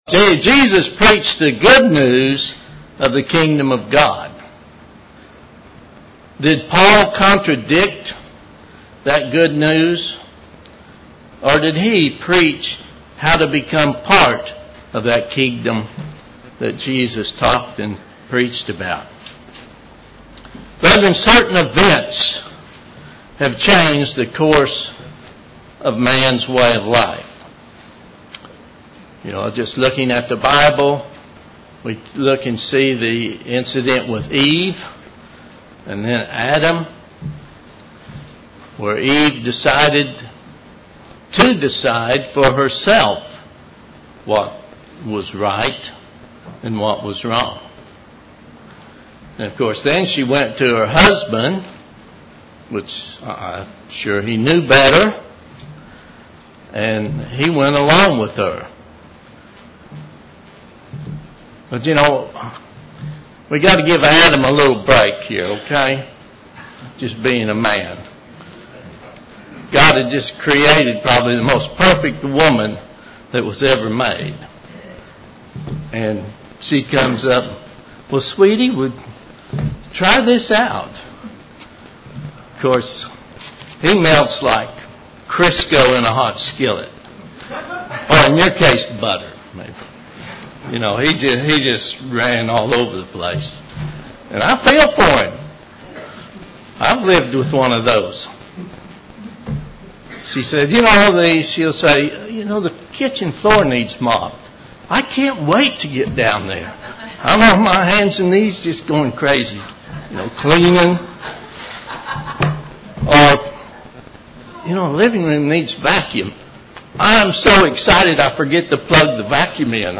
Given in Oklahoma City, OK
UCG Sermon Studying the bible?